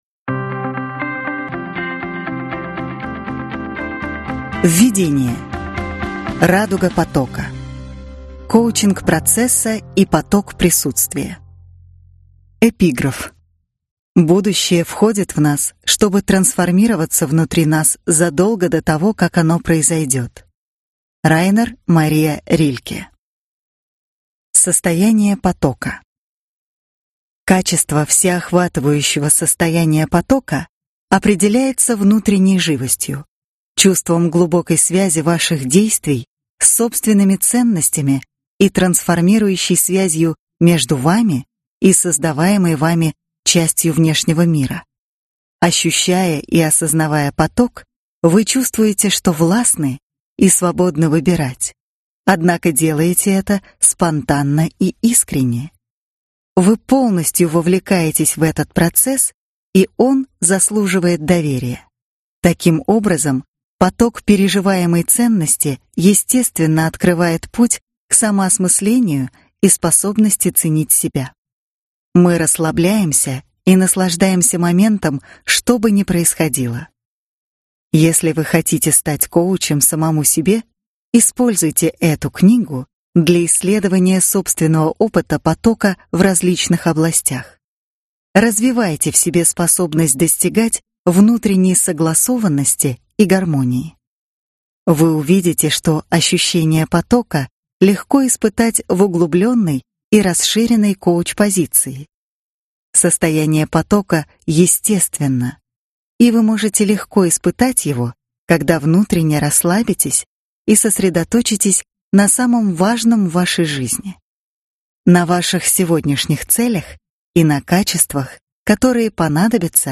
Аудиокнига Жизнь в потоке: Коучинг | Библиотека аудиокниг